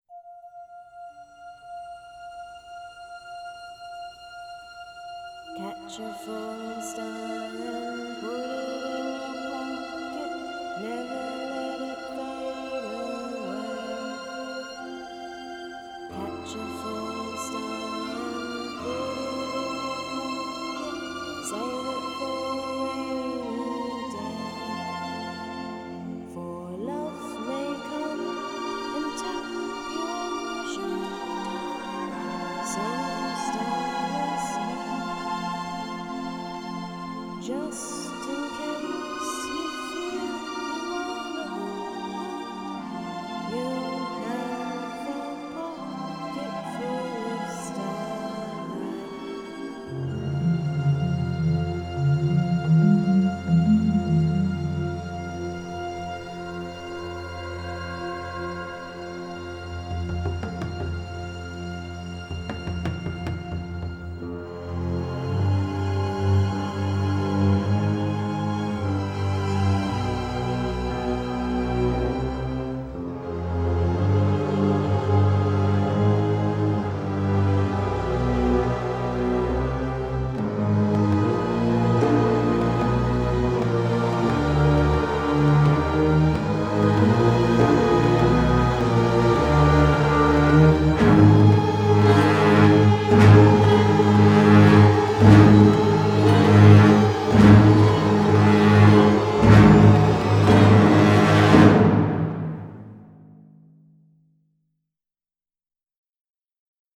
It is a very hopeful song.
Genre: Pop